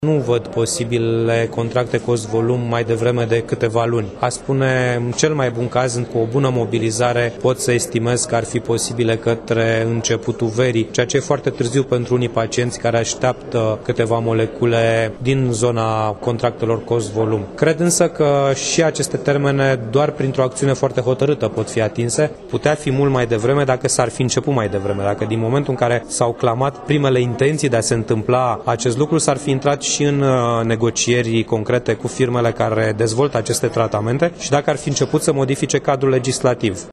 La rândul său, europarlamentarul Cristian Buşoi nu se declară la fel de optimist în ceea ce priveşte ieftinirea medicamentelor: